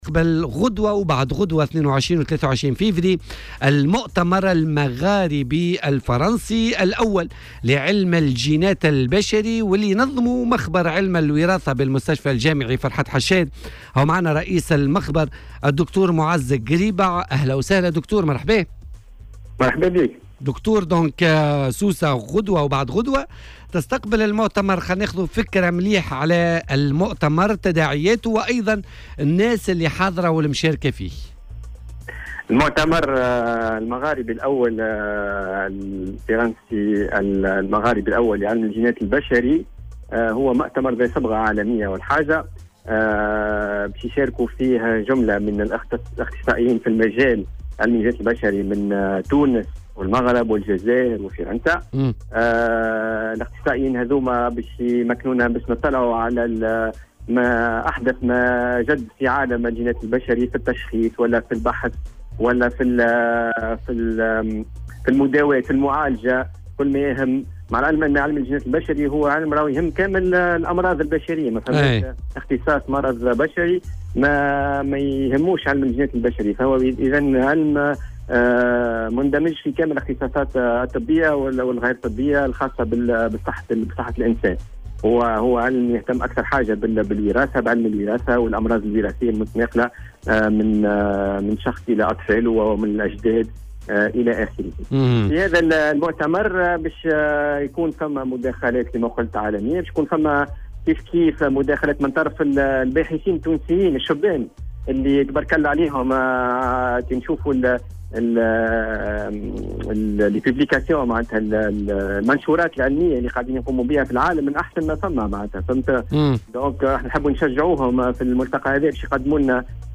وأعلن في مداخلة هاتفية مع "الجوهرة اف أم" تنظيم المؤتمر المغاربي الفرنسي الأول لعلم الجينات البشرية الذي ينظمه مخبر علم الوارثة بالمستشفى الجامعي فرحات حشاد يومي 22 و23 فيفري في سوسة.